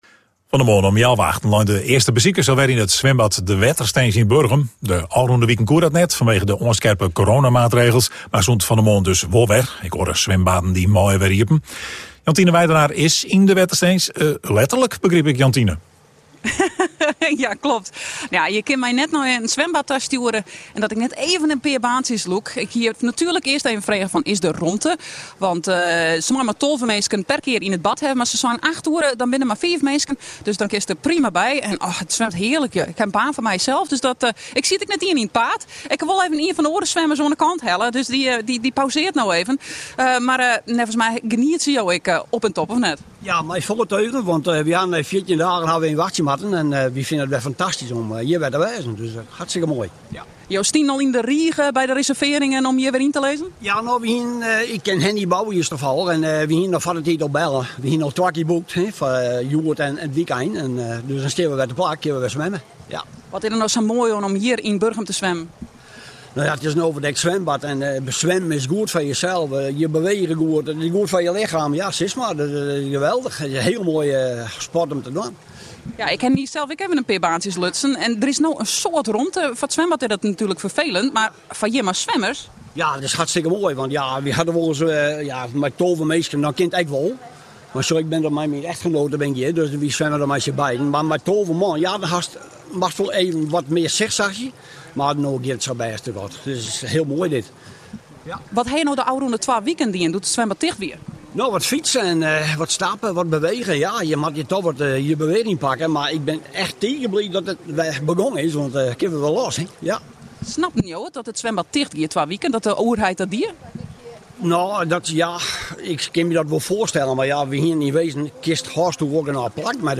dook het water in bij de Wetterstins